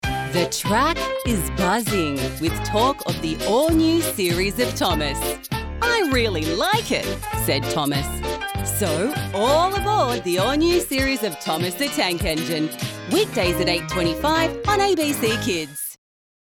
Radio & TV Commercial Voice Overs Talent, Artists & Actors
Adult (30-50)